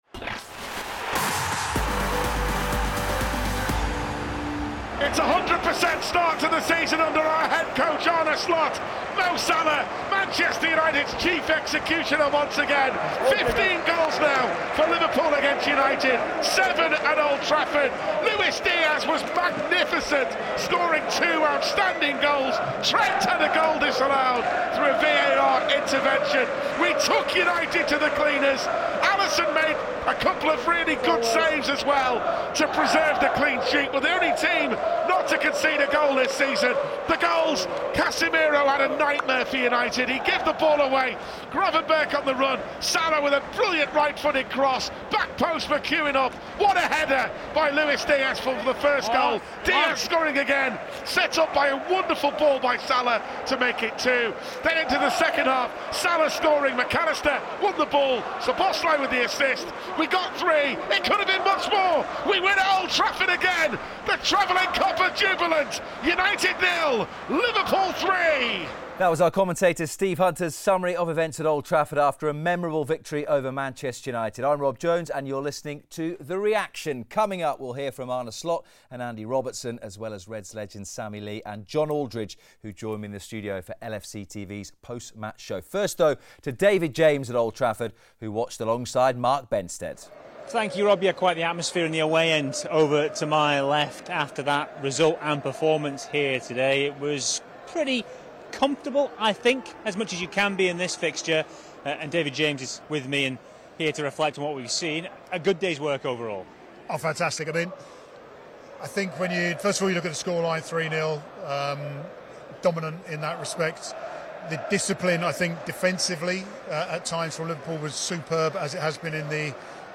Hear from Arne Slot and Andy Robertson on a memorable 3-0 victory over Manchester United after a Luis Diaz brace and another goal from Mo Salah made it three wins from three for Liverpool’s new head coach heading into the international break. Former Reds Sammy Lee, John Aldridge and David James also provide analysis of the key talking points from Old Trafford.